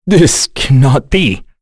Kain-Vox_Dead_b.wav